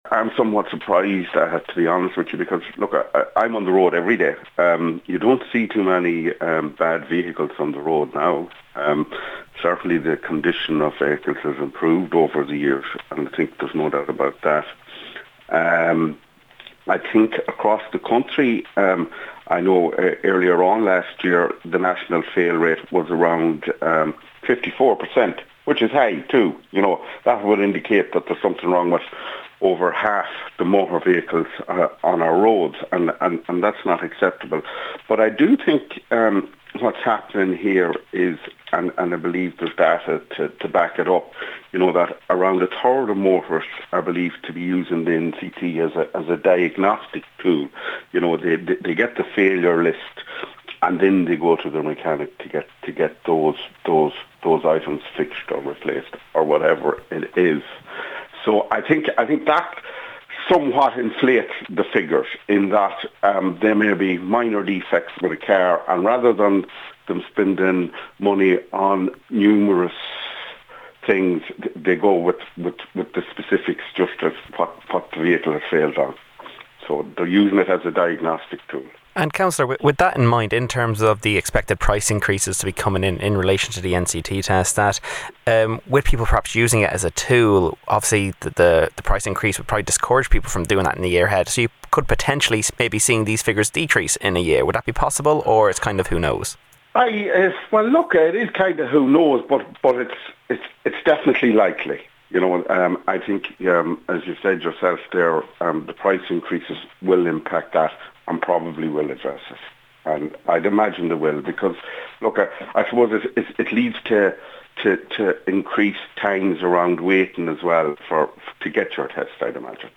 Cllr Fallon says it's surprising as most vehicles seen on the road are of an acceptable standard: